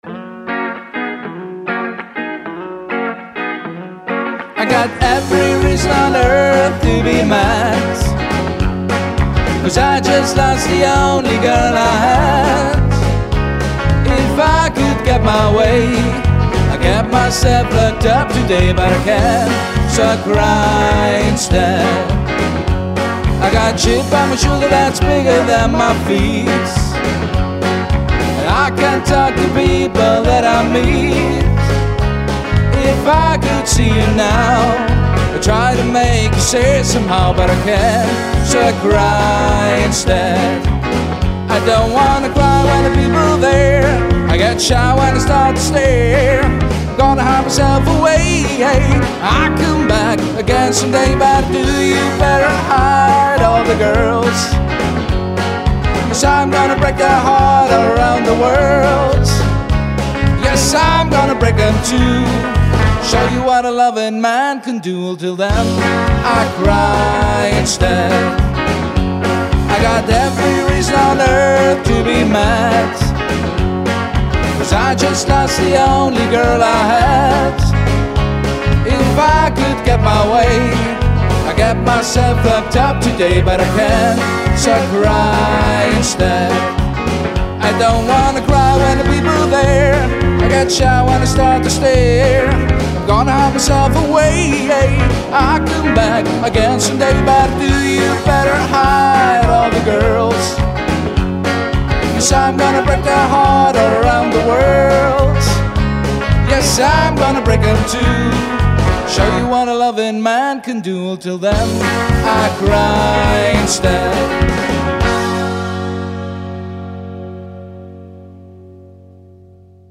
Partyband und Stimmungsband
• Rockband
• Coverband